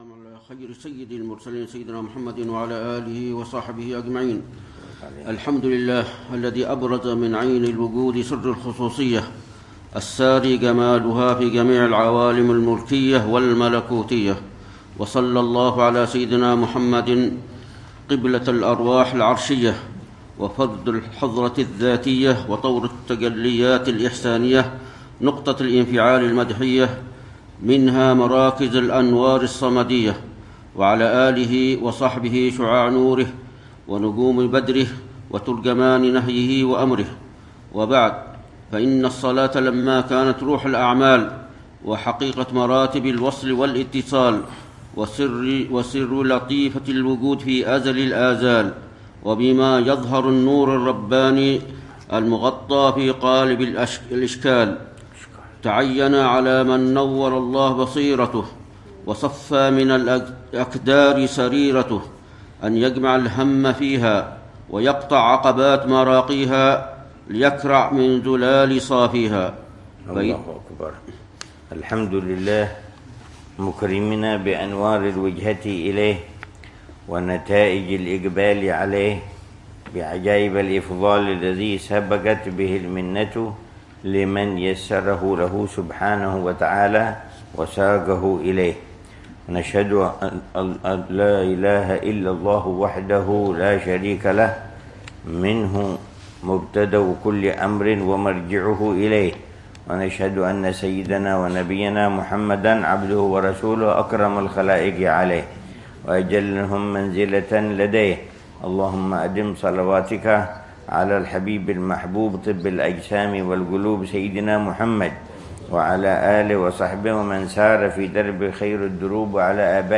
الدرس الأول من شرح العلامة الحبيب عمر بن حفيظ لكتاب صفة صلاة المقربين للعلامة الحبيب الحسن بن صالح البحر الجفري رحمه الله، يوضح فيها صفة صلاة